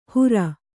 ♪ hura